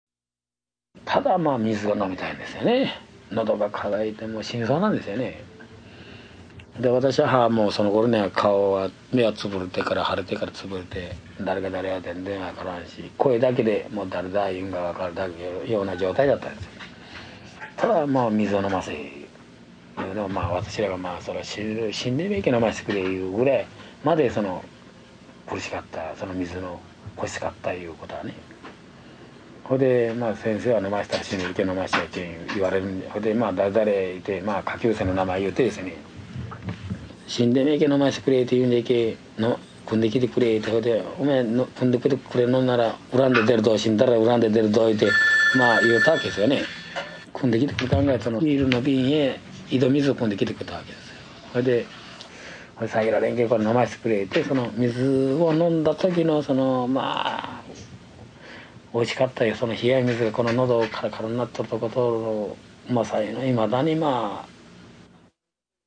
広島の被爆者の声（２） （２枚目のＣＤ の３１から３６まで）